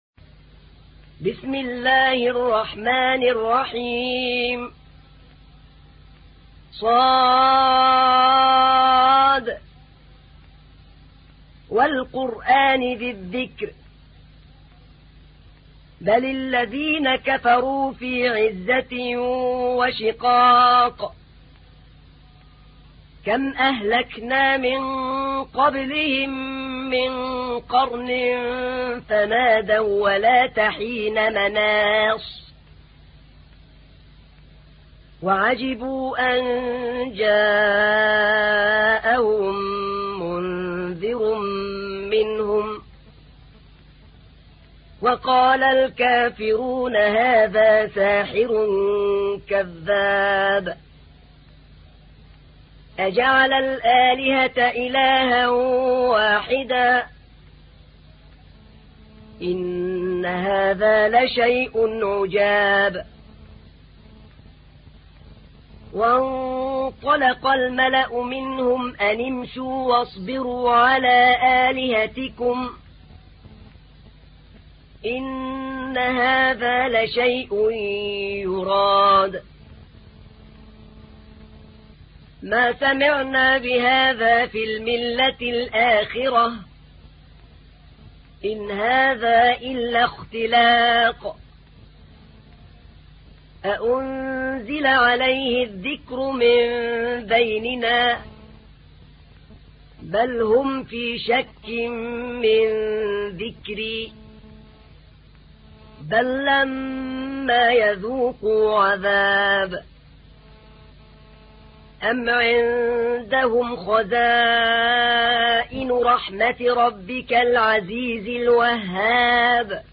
تحميل : 38. سورة ص / القارئ أحمد نعينع / القرآن الكريم / موقع يا حسين